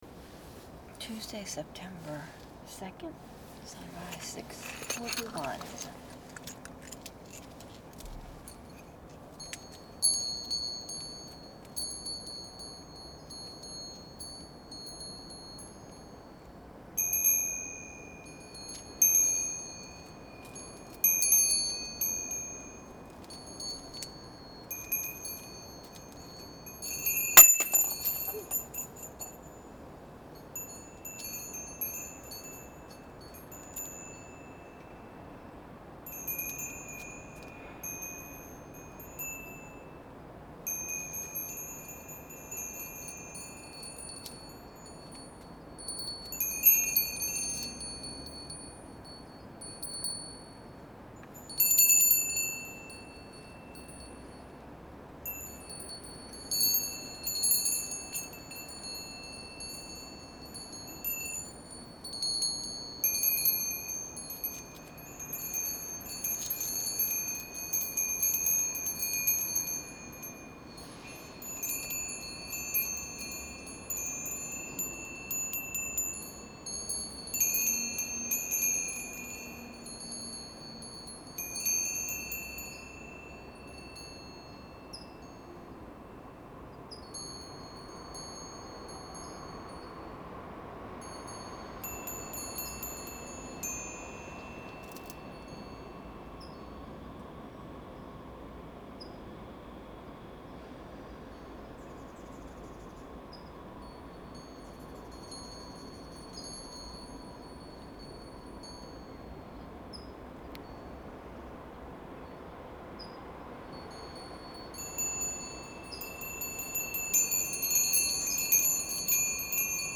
Another day out in the back yard.